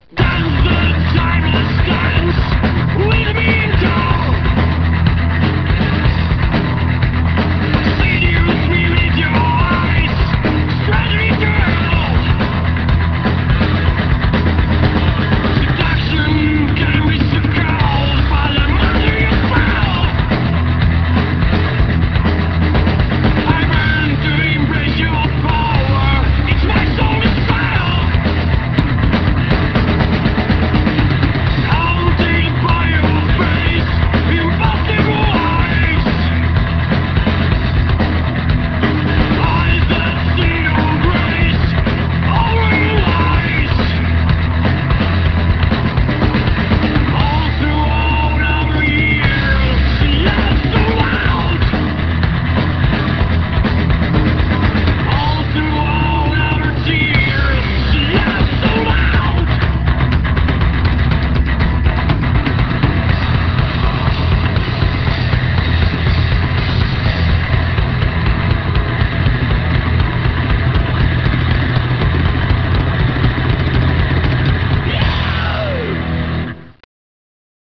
194 kB MONO